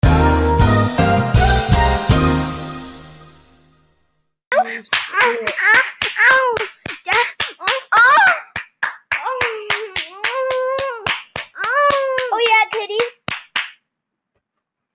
sussy - Bouton d'effet sonore